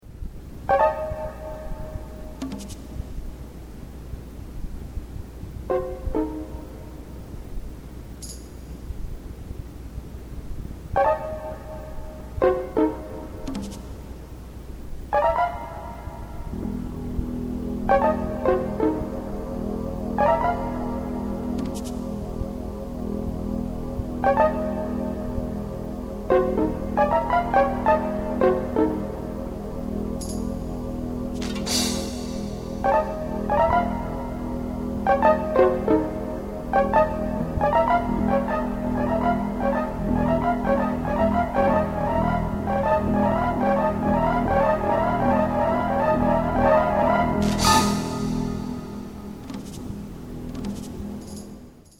spine-tingling original score